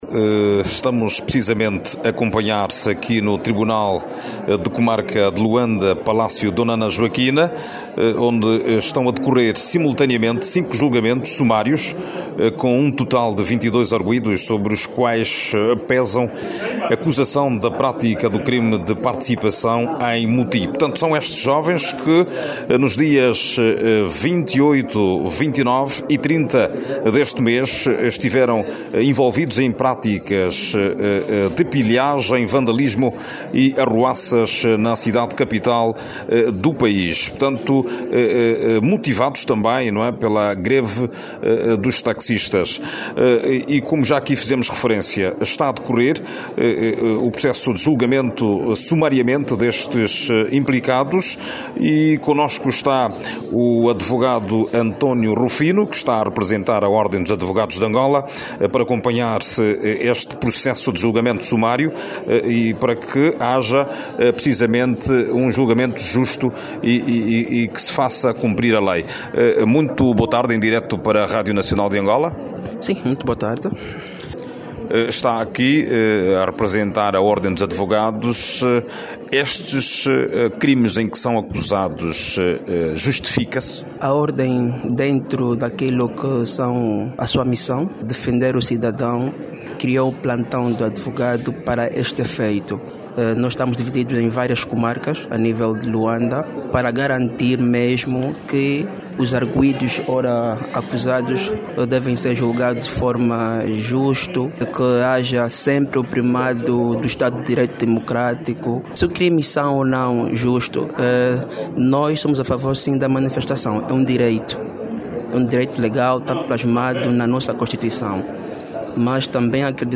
O Tribunal de comarca de luanda, prossegue hoje, quinta-feira(31), com o julgamento de cidadãos acusados de pratica de atos de vandalismo arruaça e pilhagem durante a greve dos taxistas que decorreu entre 28 a 30 deste mês. Clique no áudio abaixo e ouça a reportagem